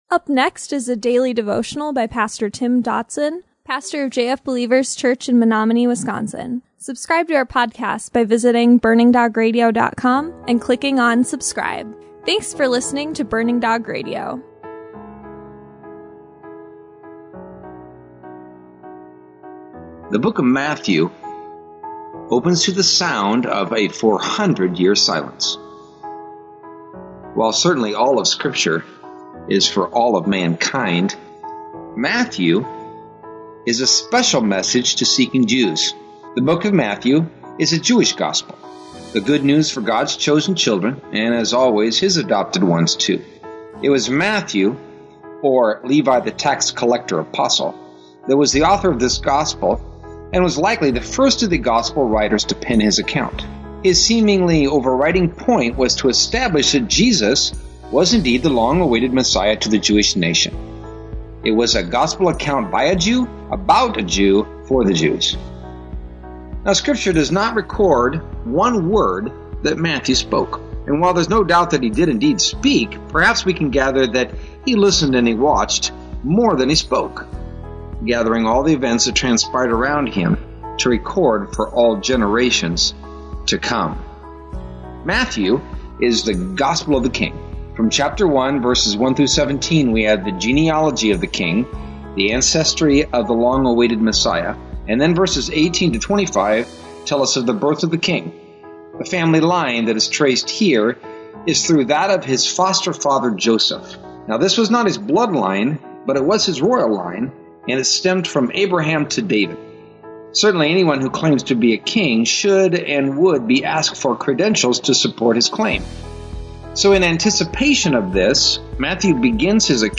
March 2019 – Daily Devotions